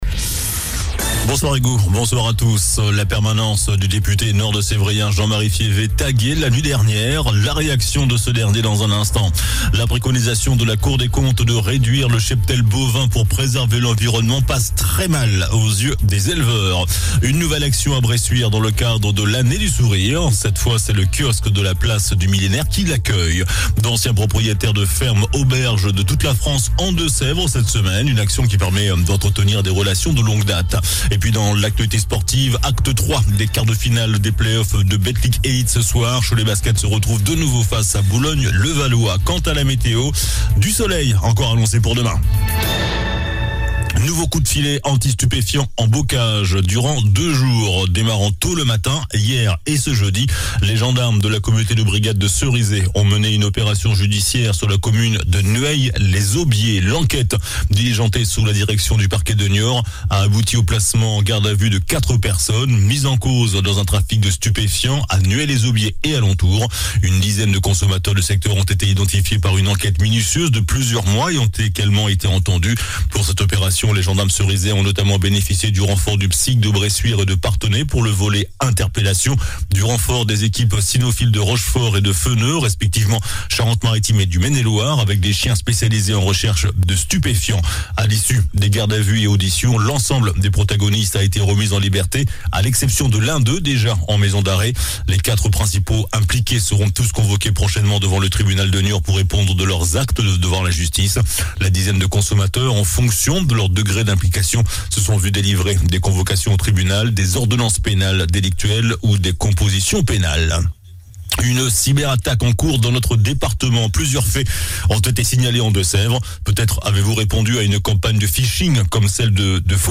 JOURNAL DU JEUDI 25 MAI ( SOIR )